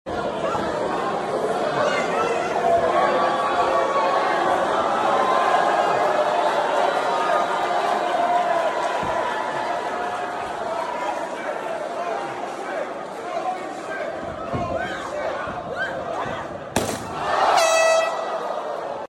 Samurai Light Tube Sword used sound effects free download